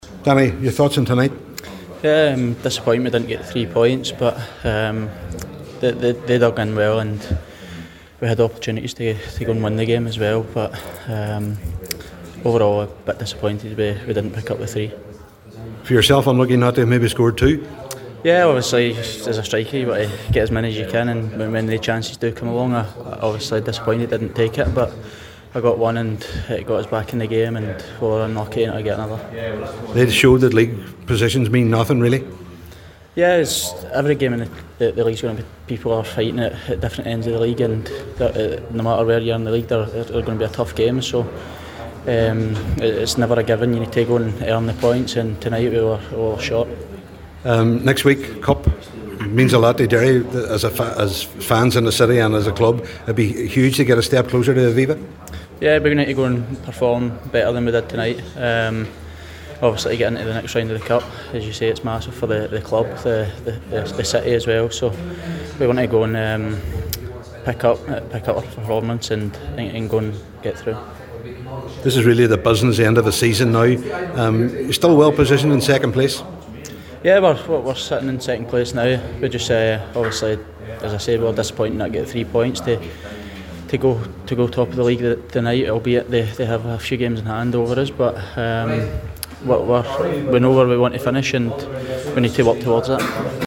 after full time at the Brandywell…